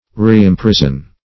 Reimprison \Re`im*pris"on\ (-pr?z'n), v. t. To imprison again.
reimprison.mp3